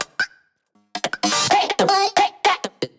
这些循环专为地下电子音乐打造，容量为 145 MB，包含 24 位 WAV 格式、160 BPM 的音频文件，可直接导入你的项目。
• 160 BPM